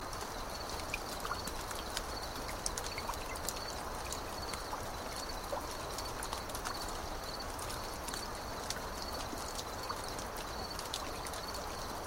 crickets.ogg